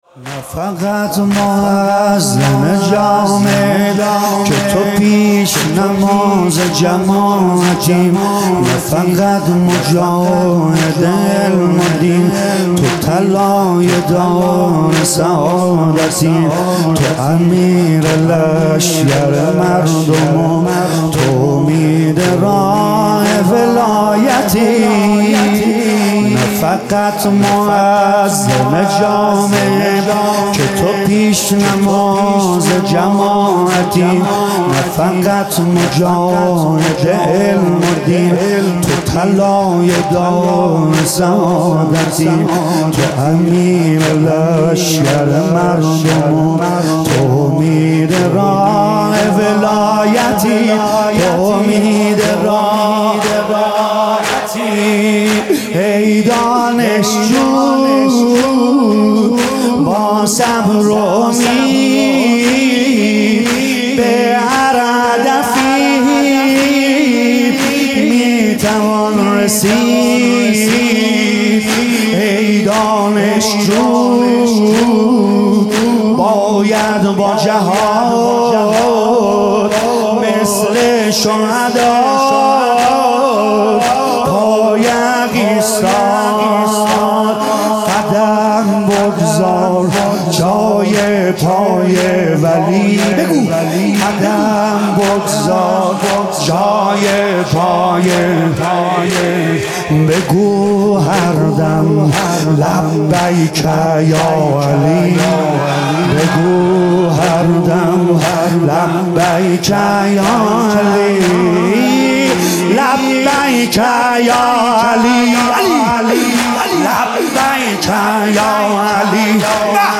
music-icon تک: واحد آخر جنون برای ما شهادته...